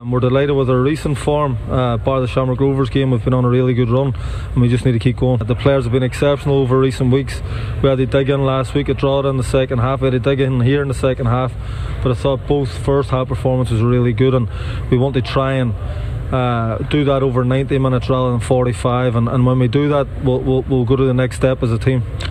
Speaking after the Friday win against Bohemians – Higgins stated his belief there’s more to come from his team